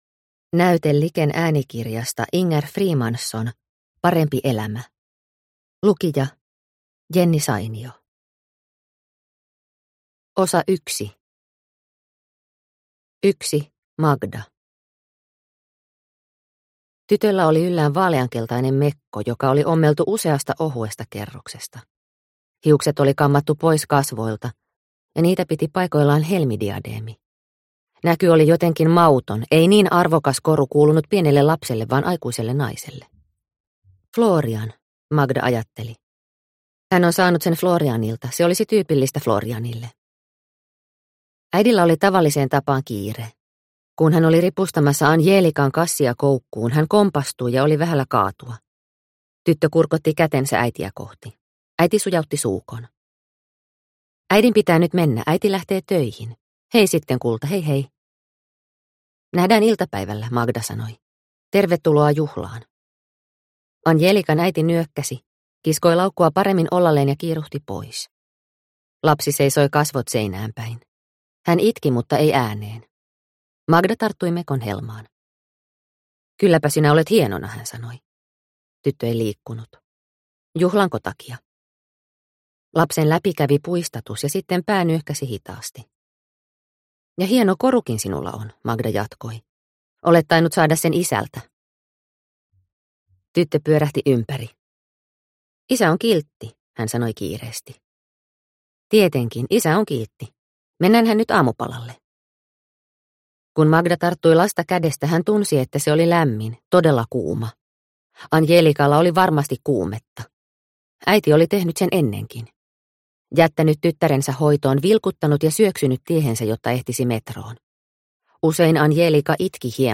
Parempi elämä – Ljudbok – Laddas ner